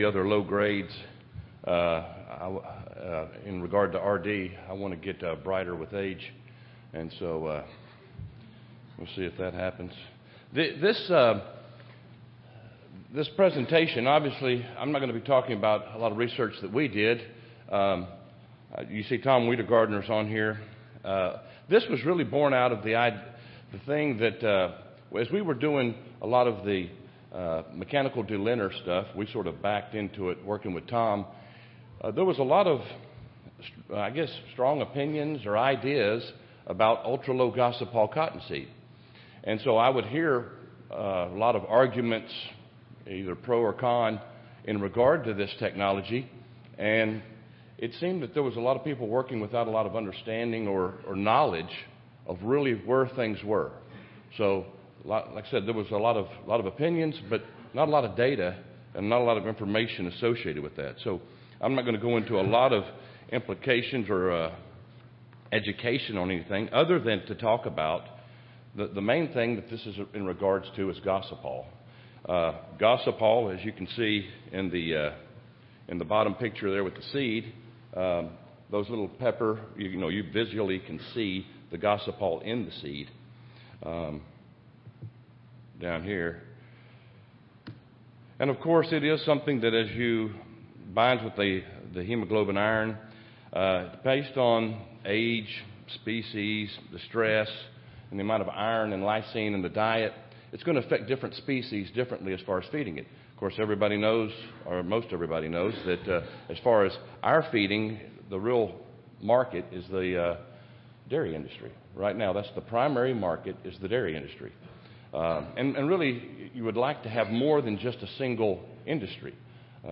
Recorded Presentation
Cotton Ginning Conference